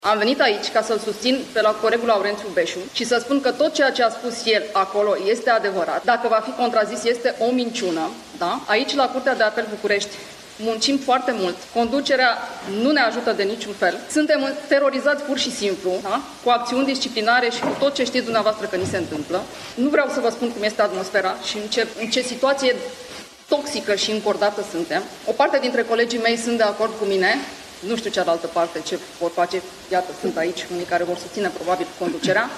Curtea de Apel București a organizat o conferință de presă, iar conducerea instituției a criticat acuzațiile lansate în documentarul Recorder.
Cu toatea aceastea, judecătorul Raluca Moroşanu a intervenit la începutul conferinței, la Curtea de Apel Bucureşti, și a precizat că susţine afirmaţiile colegului său Laurenţiu Beşu din documentarul Recorder. Ea a descris atmosfera din instanţă ca fiind „toxică şi încordată” și a adăugat că sunt judecătorii sunt terorizaţi prin acţiuni disciplinare.